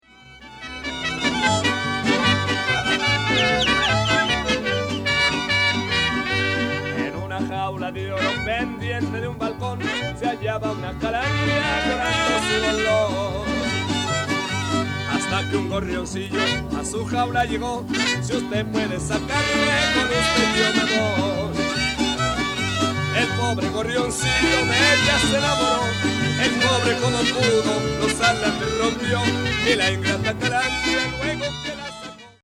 Chapter 11. Mexican Mariachi
Spontaneous recording of the calls, whistles, shouted insults and jokes with which the tourist or visitor is enticed into engaging the band.